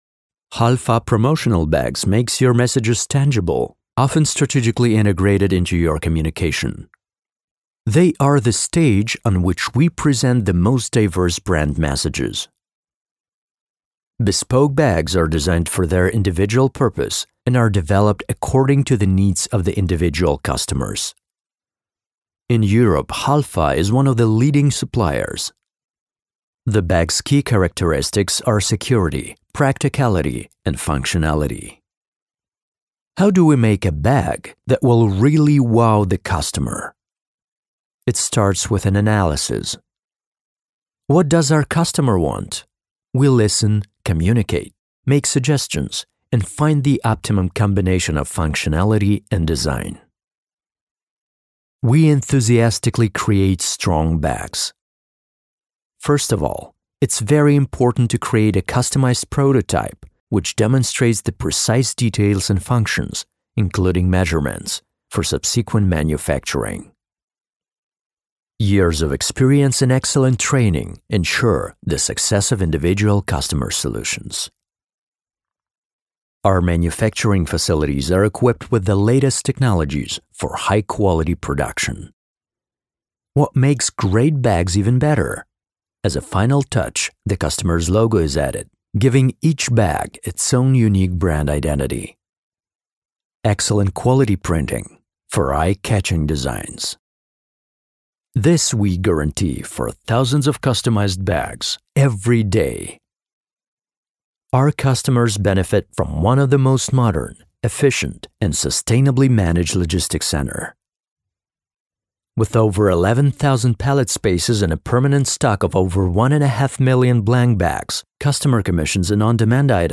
Native speaker Male 30-50 lat
Multilingual voice artist whose second native language is American English.
Prezentacja korporacyjna w języku angielskim